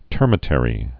(tûrmĭ-tĕrē)